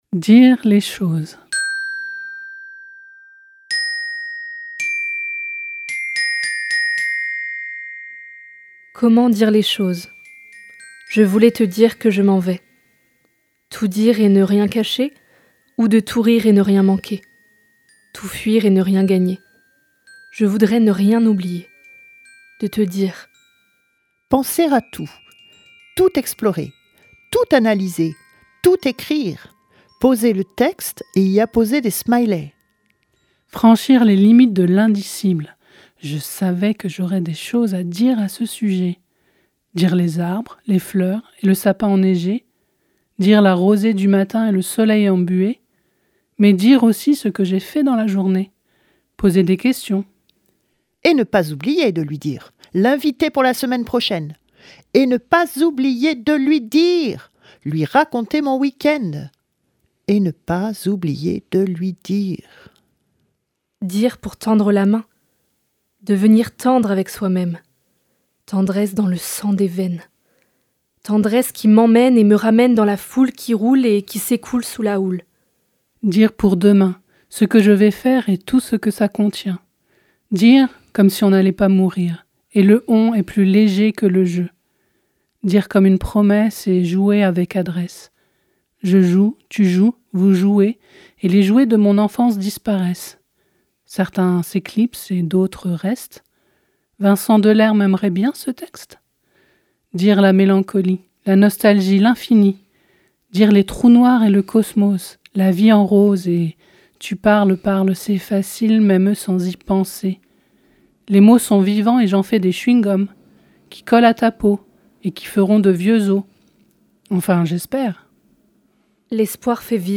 🎧 Dire les choses - Les ateliers de fictions radiophoniques de Radio Primitive